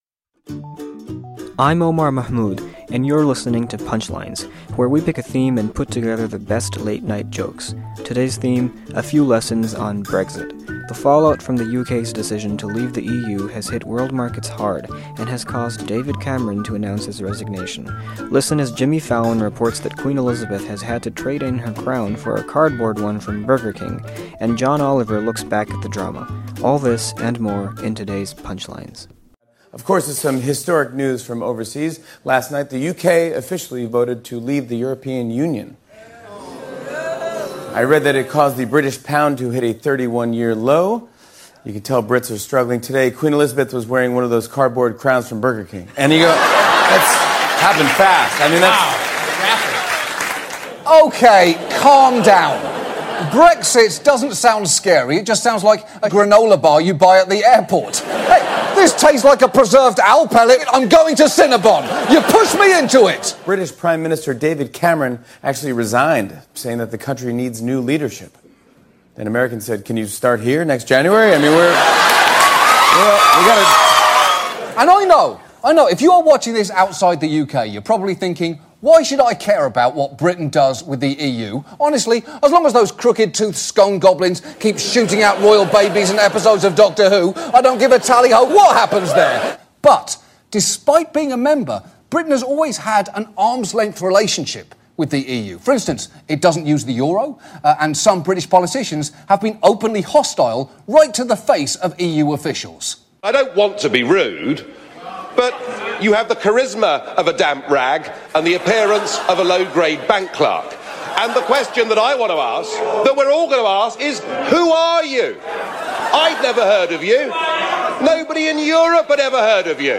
Late-night comics take a look at the UK's vote to exit the European Union.